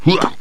daemon_attack8.wav